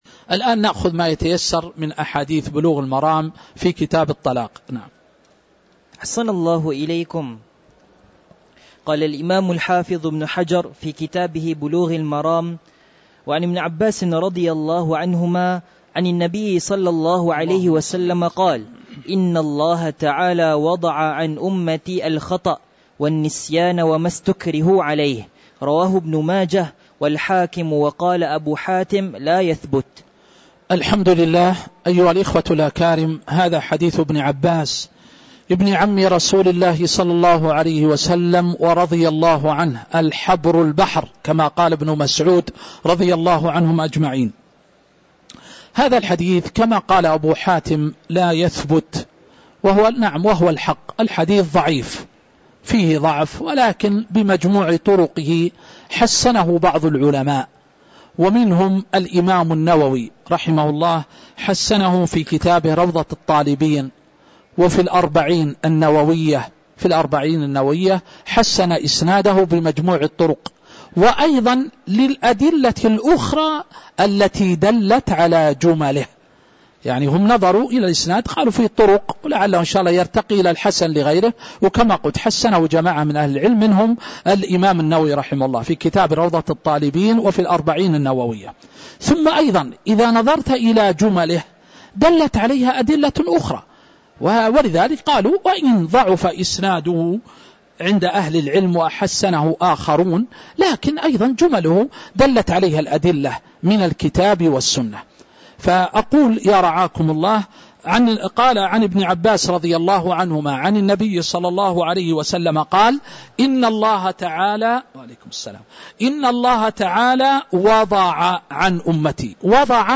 تاريخ النشر ٢٨ شوال ١٤٤٦ هـ المكان: المسجد النبوي الشيخ